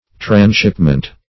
Transshipment \Trans*ship"ment\, n.